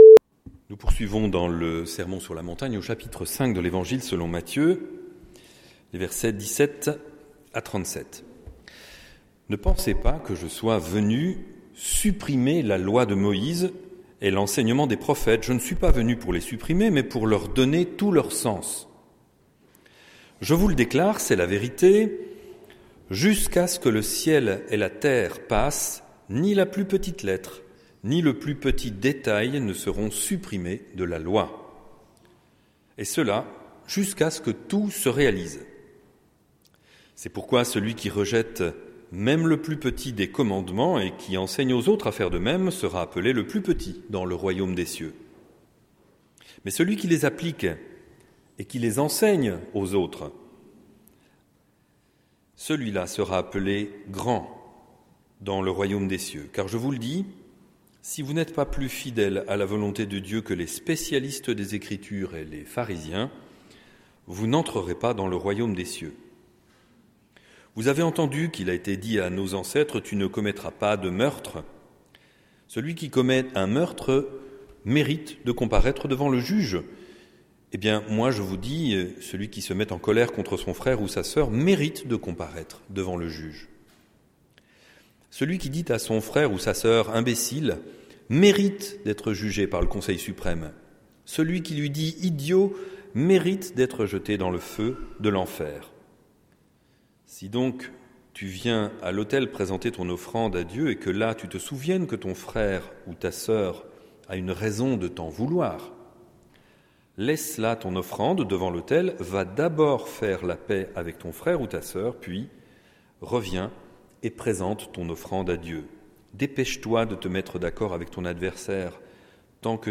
Prédication du 12/02/2023